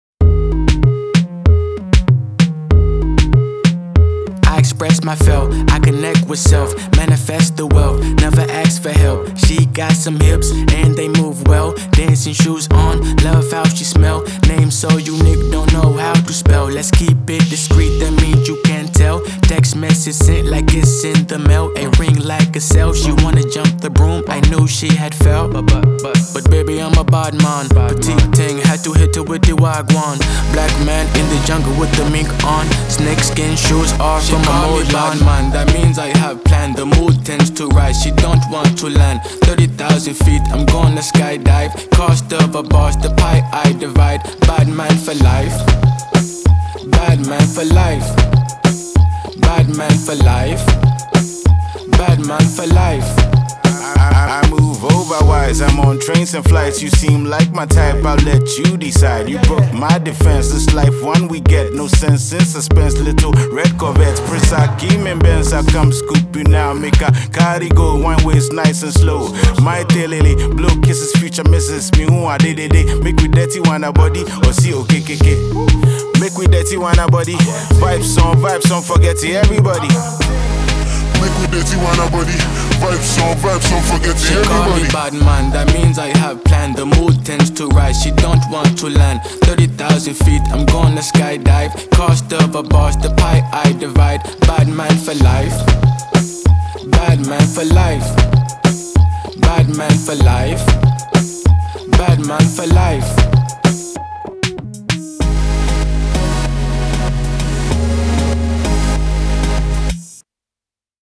Highly-rated Ghanaian rapper